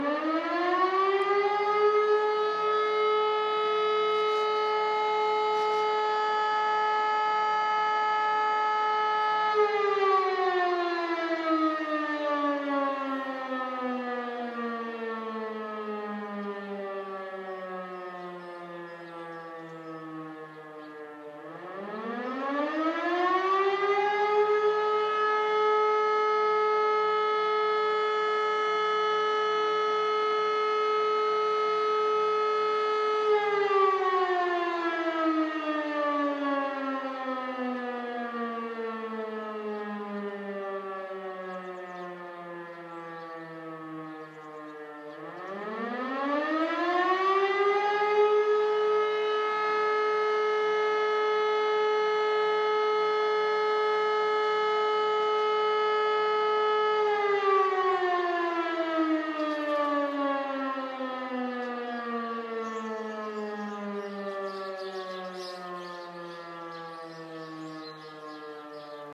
Alarmierung der Feuerwehr
(Sirene der Feuerwehr Niedergebraching beim Probealarm am 04.04.2015)
Sirene.mp3